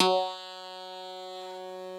genesis_bass_042.wav